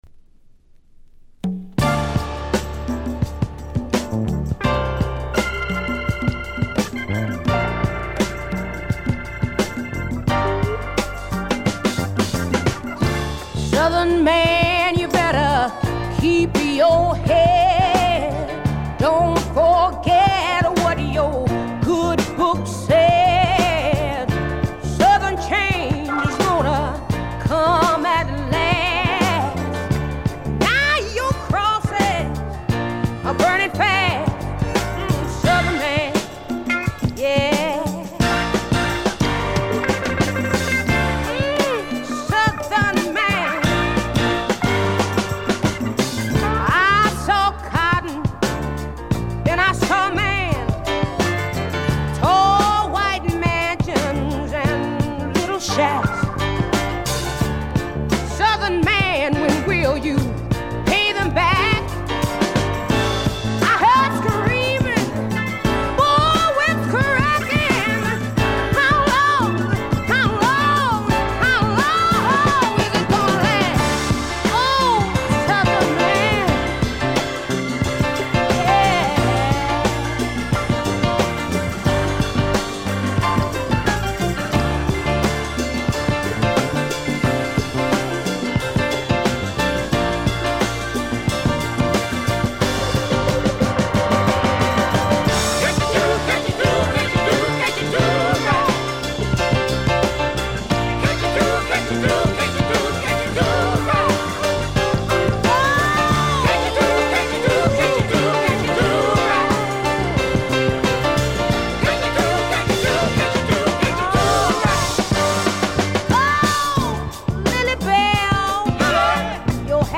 試聴曲は現品からの取り込み音源です。
Recorded At - A&M Studios